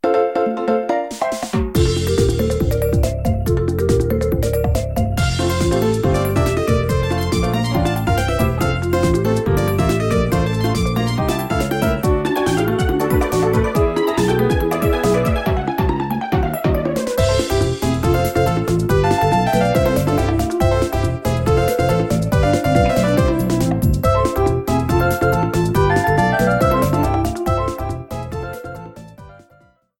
Reduced length to 30 seconds, with fadeout.